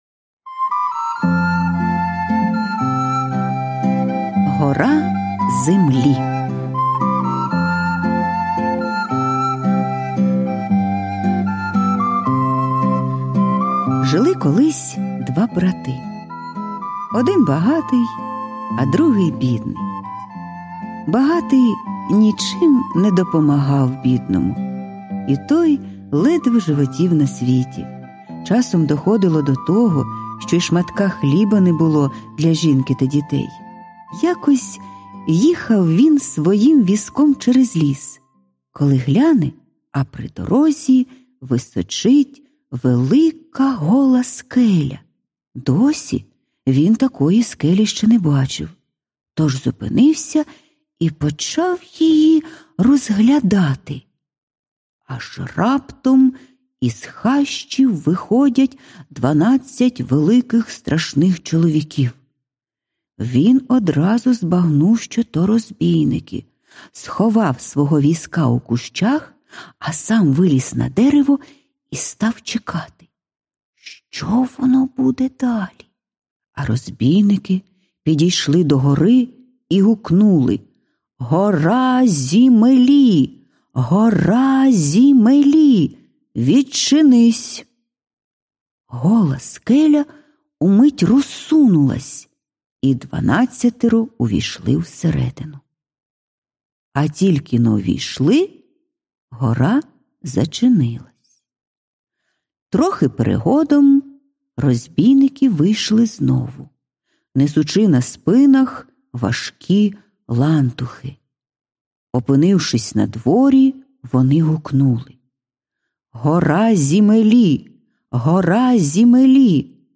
Аудіоказка Гора землі
Жанр: Літературна / Пригоди / Фантастика